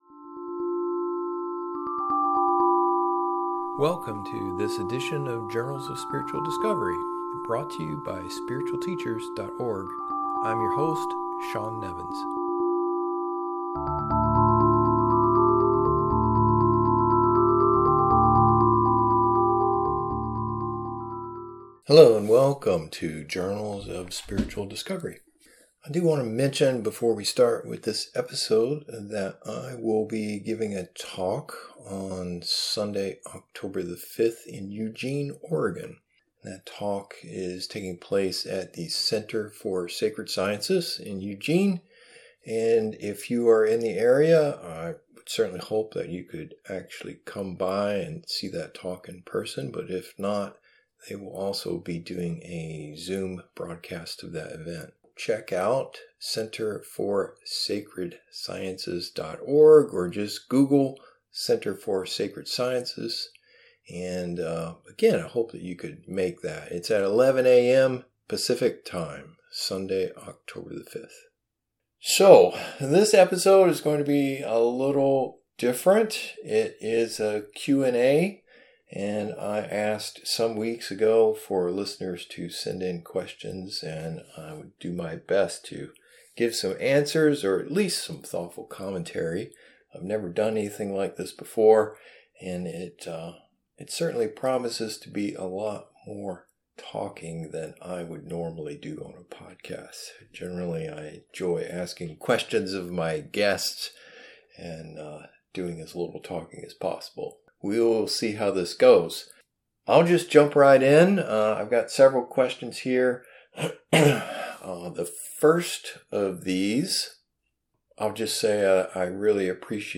In this spiritual Q&A episode, I respond to listener-submitted spiritual questions spanning a variety of themes.